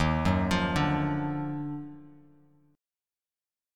D#mM9 Chord